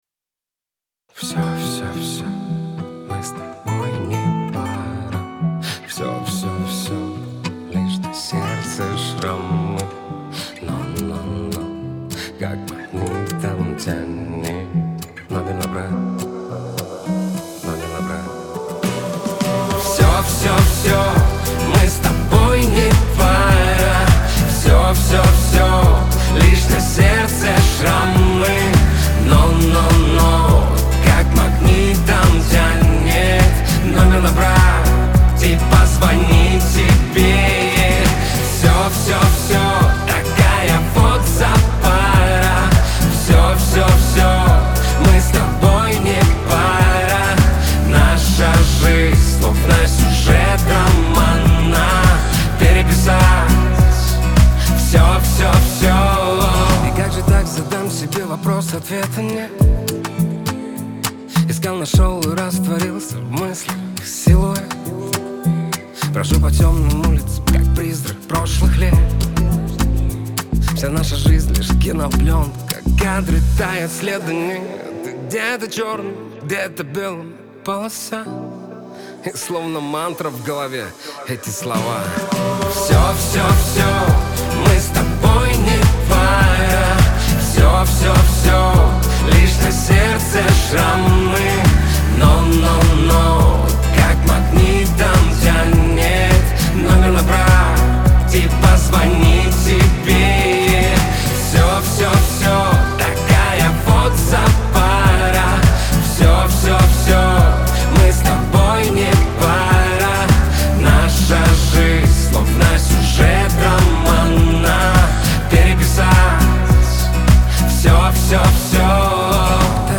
эстрада
pop , диско